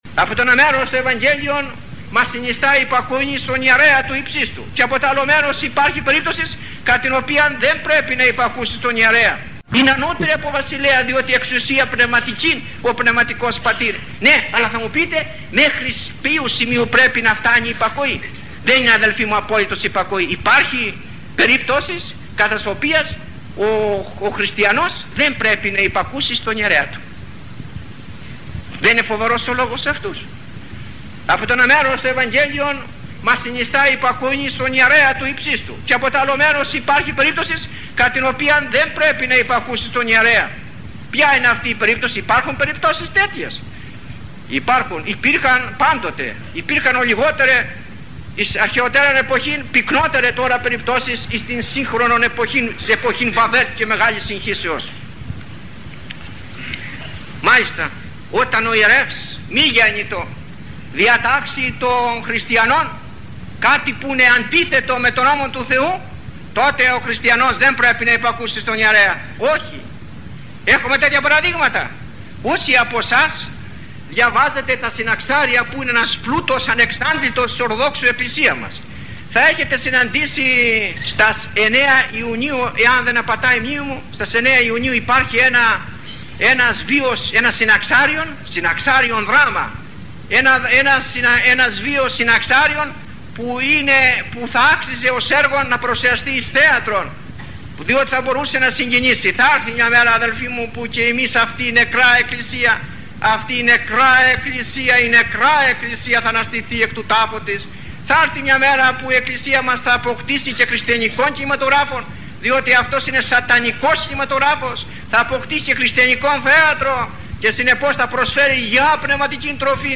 Υπακοή και ανυπακοή στους πνευματικούς μας ηγέτες, είναι το θέμα της ομιλίας του αειμνήστου Μητροπολίτου Φλωρίνης, Αυγουστίνου Καντιώτου.
Ζητούμε συγνώμη για την μάλον κακή ποιότητα του ήχου, μα εκείνο που ενδιαφέρει σ’ αυτές τις περιπτώσεις, νομίζουμε, είναι τα λεγόμενα.